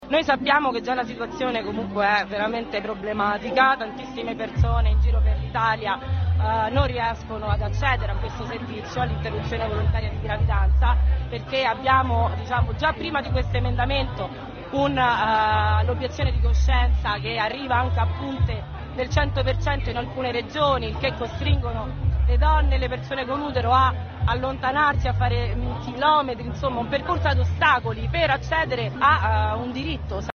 A Roma sabato scorso Non una di meno è scesa in piazza contro l’emendamento che incentiva la presenza dei Pro vita nei consultori. Ascoltiamo una voce della piazza